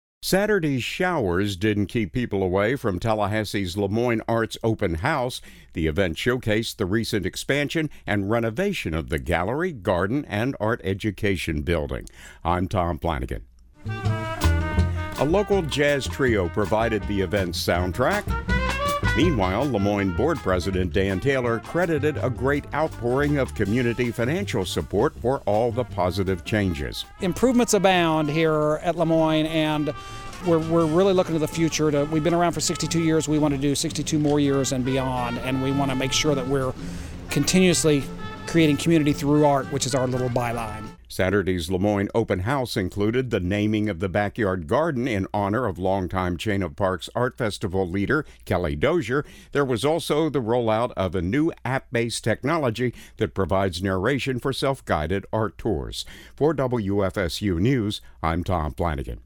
A local jazz trio provided the event’s soundtrack.